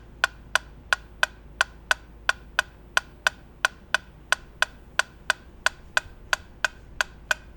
나도_모르게_메트로놈.mp3